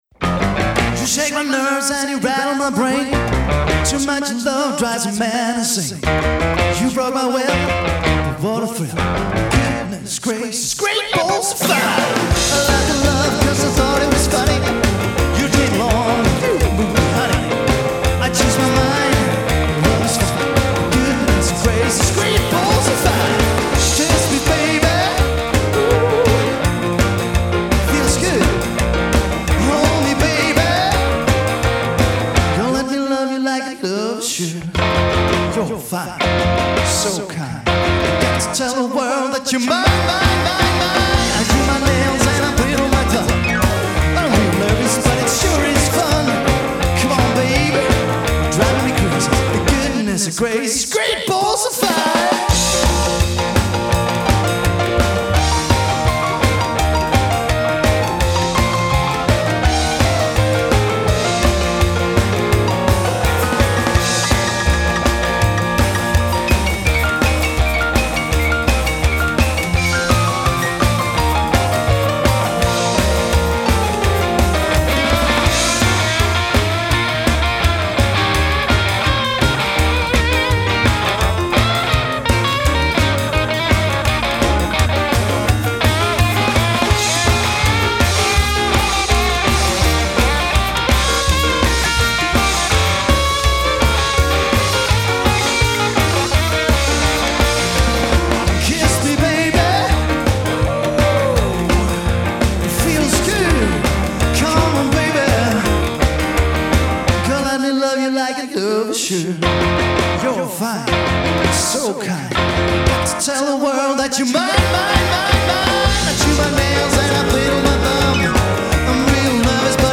The greatest rock 'n roll of the 50's and 60's